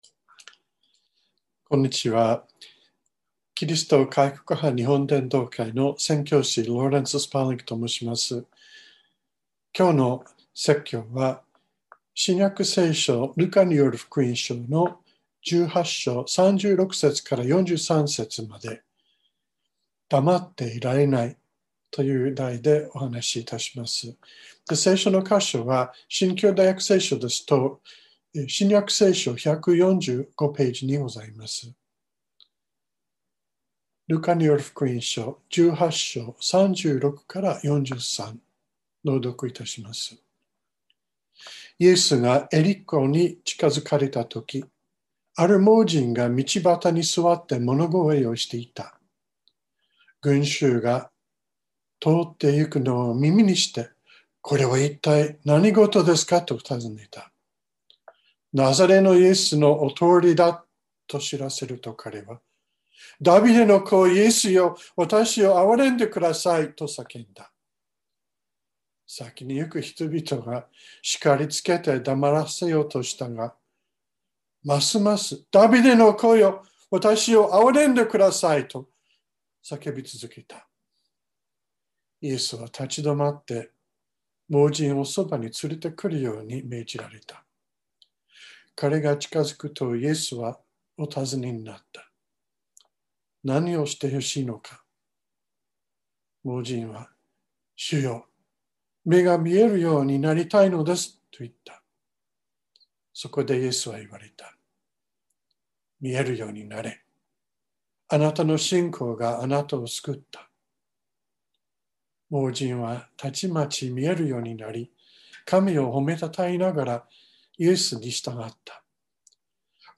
2021年02月28日朝の礼拝「黙っていられない」川越教会
川越教会。説教アーカイブ。